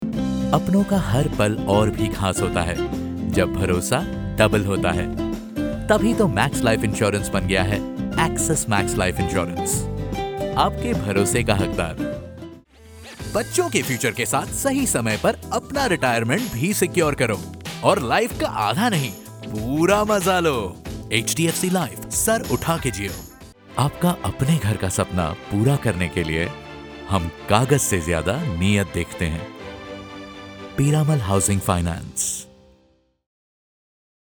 Young Catchy Conversational